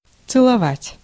Ääntäminen
Ääntäminen : IPA : /ˈkɪs/ US : IPA : [kɪs]